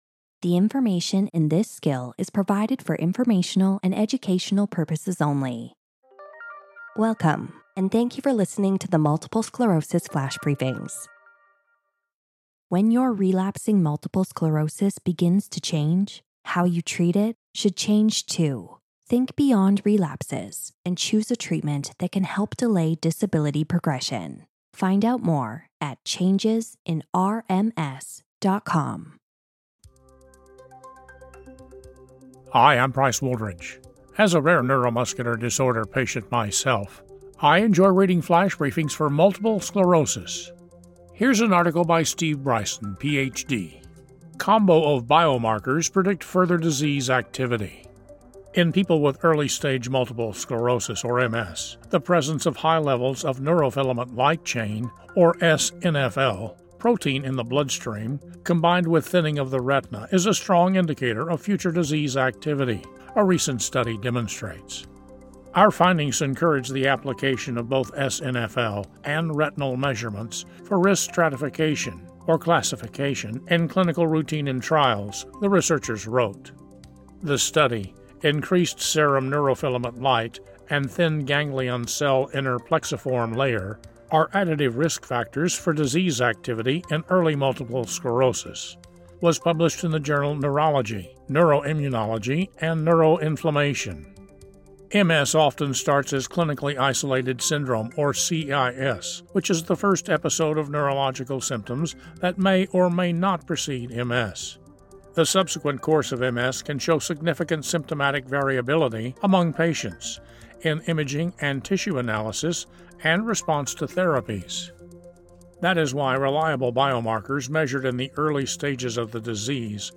reads a news article